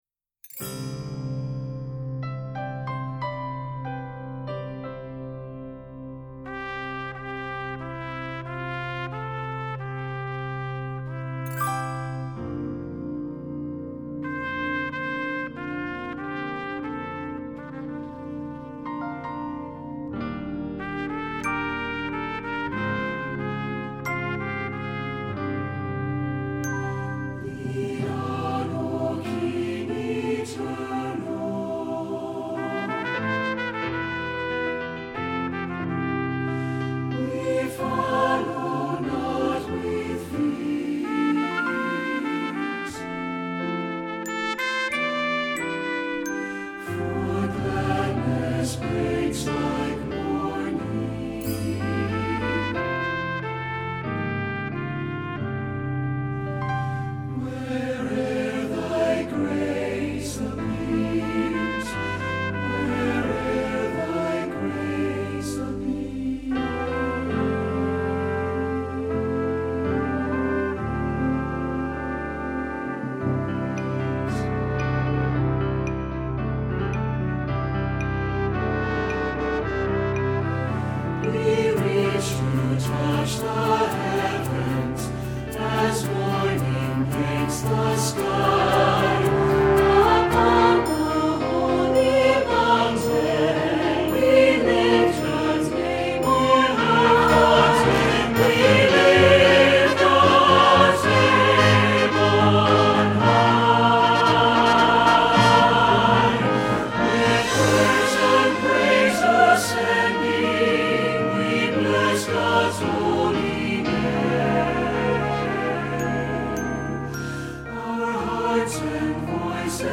Voicing: SATB and Violin